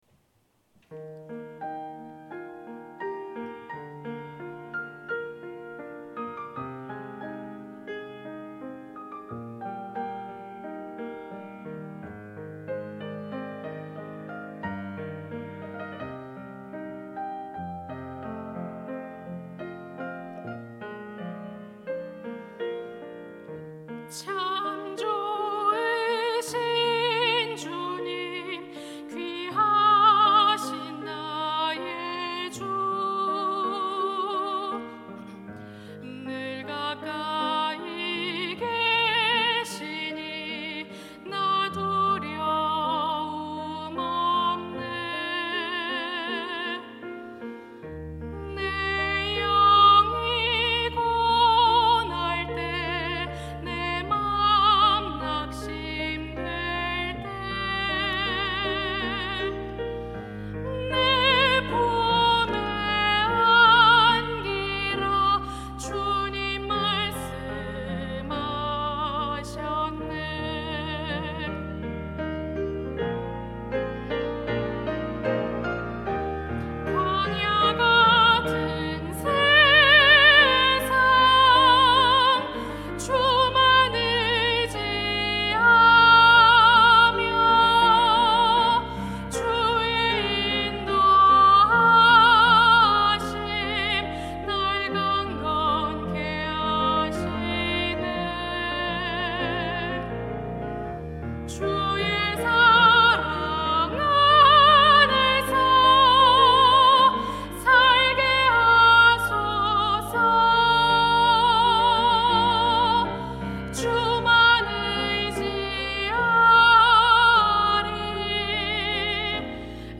임마누엘